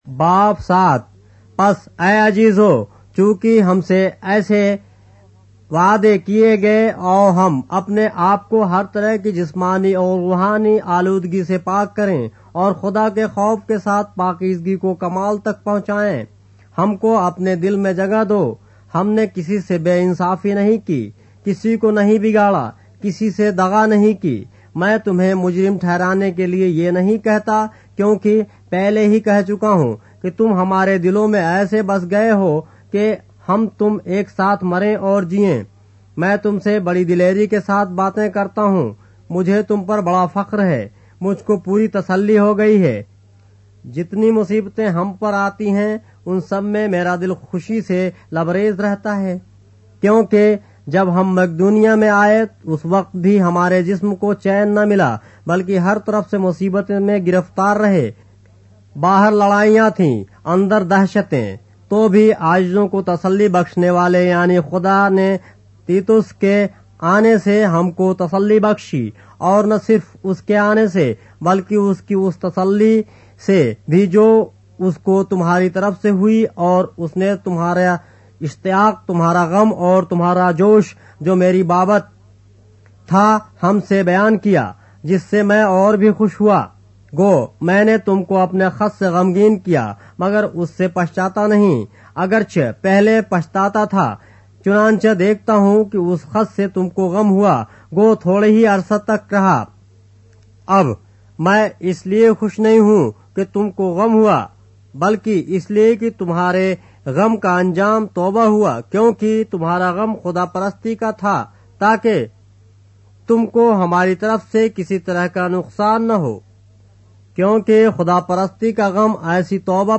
اردو بائبل کے باب - آڈیو روایت کے ساتھ - 2 Corinthians, chapter 7 of the Holy Bible in Urdu